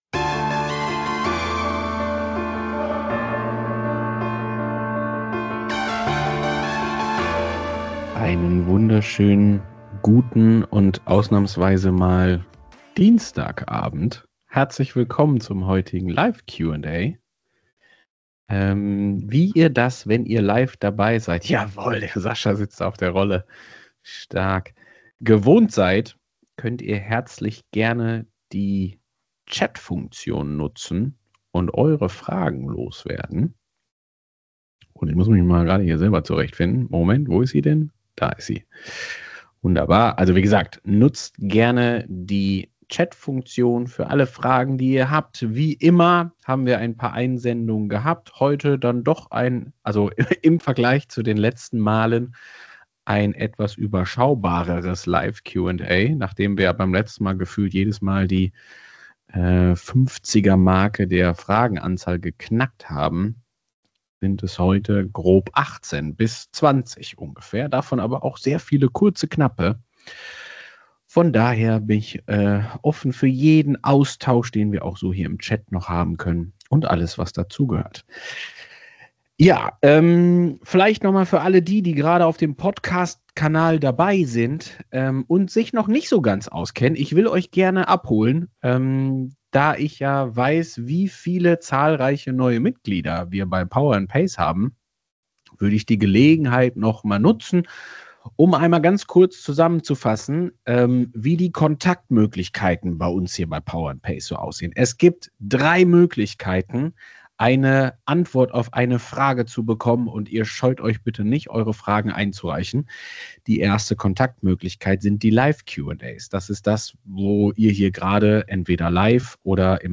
Hier ist der Mitschnitt.